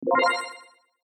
power up sfx Meme Sound Effect
This sound is perfect for adding humor, surprise, or dramatic timing to your content.
power up sfx.mp3